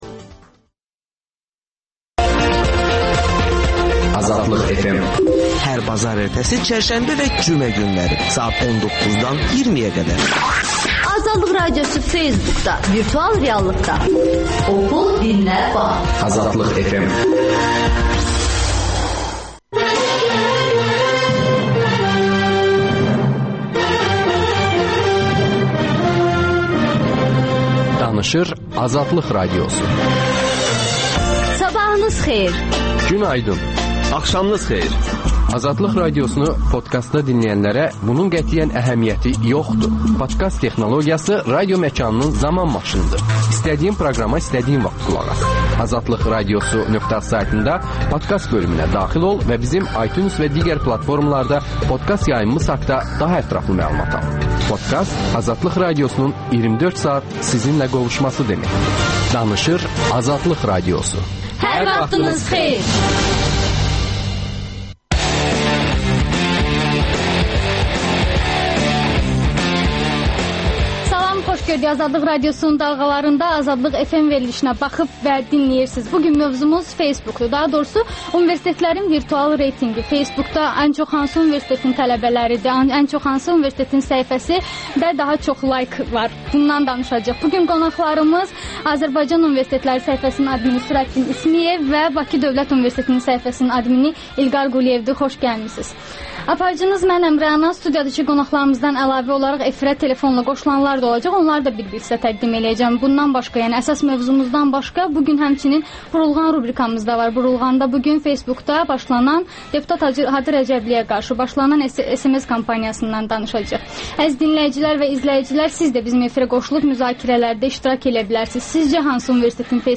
Müzakirədə tələbələr, universitetlərin səhifələrinin adminləri iştirak edir